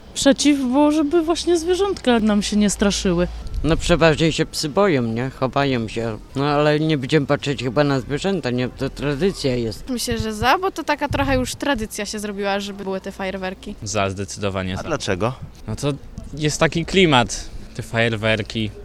Zapytaliśmy zielonogórzan, jakie jest ich zdanie na temat sztucznych ogni, które pojawią się na naszym finale Wielkiej Orkiestry Świątecznej Pomocy: